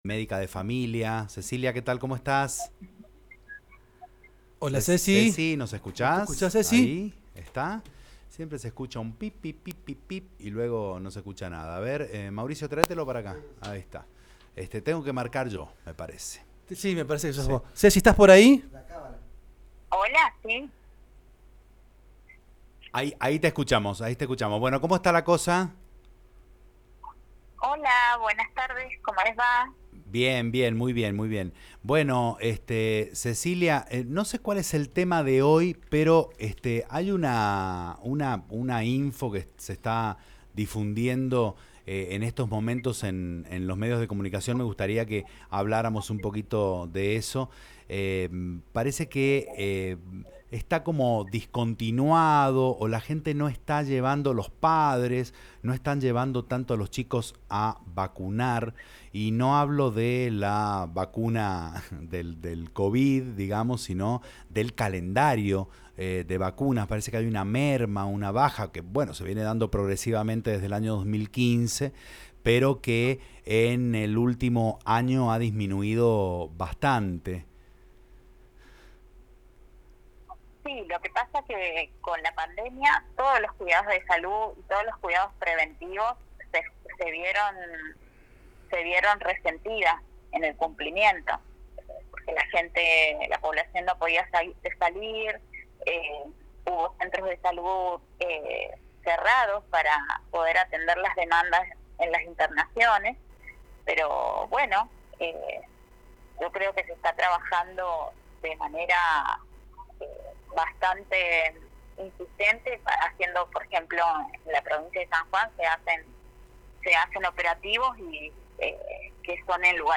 La médica de familia aclaró puntos fundamentales para el cuidado de la salud general, VIH y vacunación;en diálogo con El Cimbronazo.